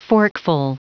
Prononciation du mot forkful en anglais (fichier audio)
Prononciation du mot : forkful